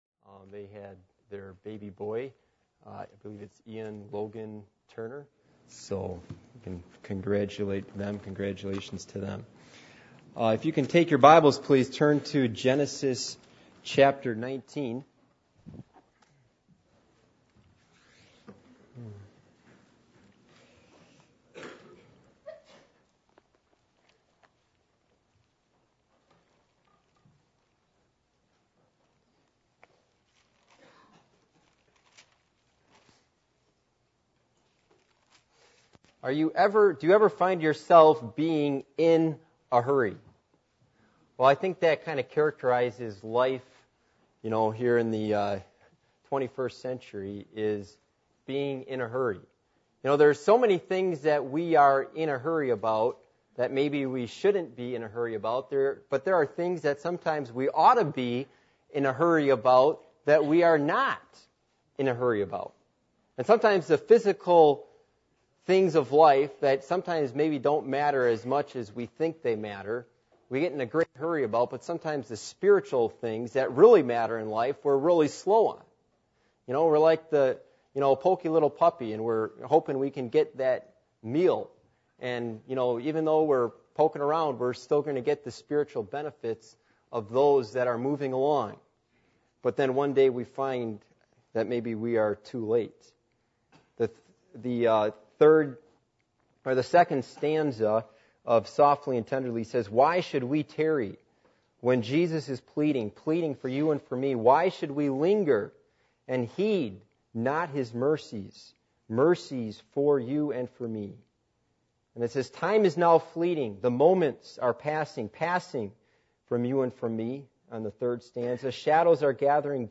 Genesis 19:1-Exodus 19:16 Service Type: Sunday Morning %todo_render% « Looking To Christ Or To The World?